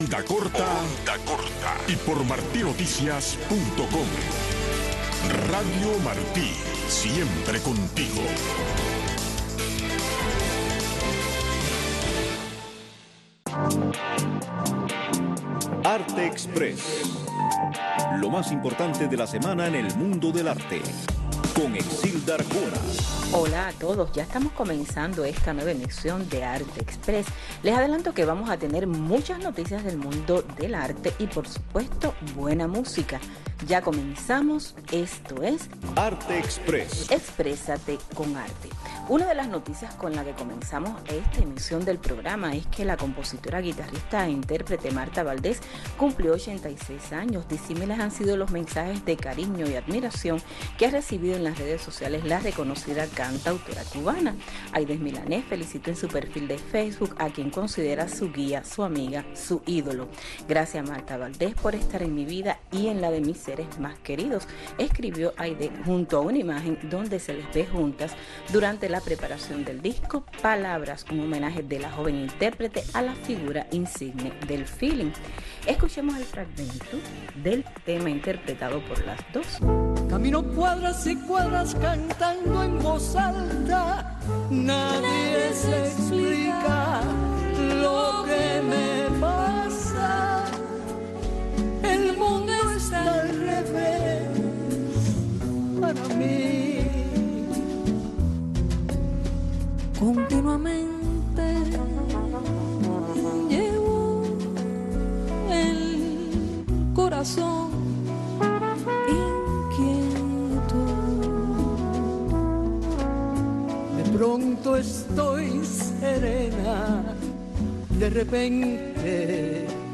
conversando con el cineasta cubano acerca de las propuestas del cine independiente en la isla.